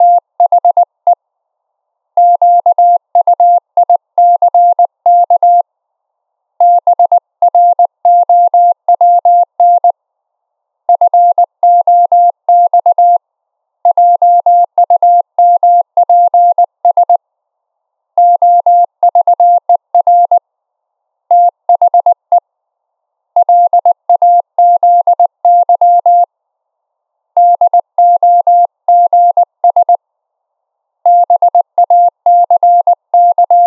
La velocità di trasmissione è 20 wpm (parole al minuto), corrispondenti a 100 caratteri al minuto.
Gli altri devono aver collegato l'uscita audio del keyer direttamente al PC.
Op E 700 Hz.wav